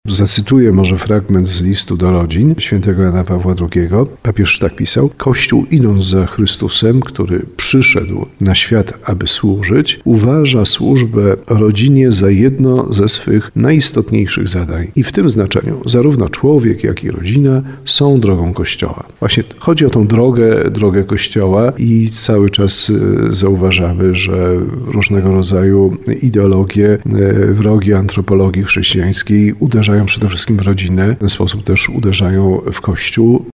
Bp Jeż w rozmowie z RDN Małopolska przypomina, że św. Jan Paweł II bardzo mocno akcentował kwestie dotyczące rodziny.